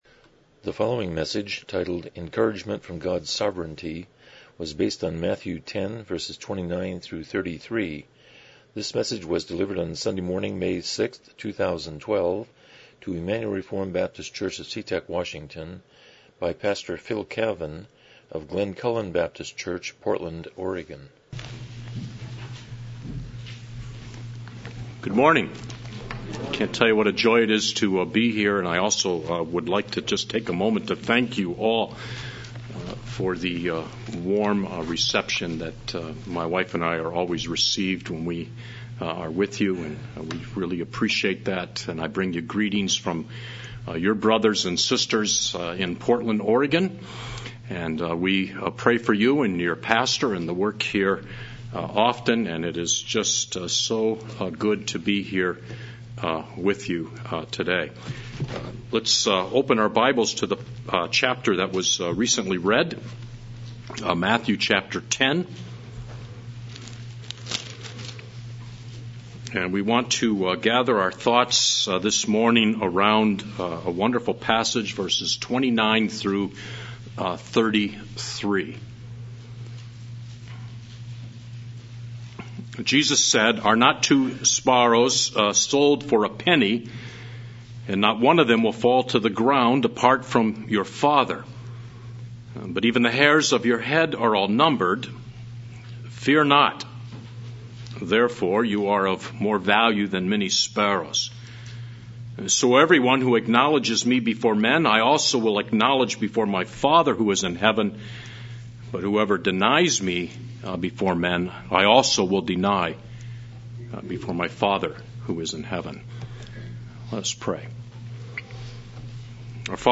Passage: Matthew 10:29-33 Service Type: Morning Worship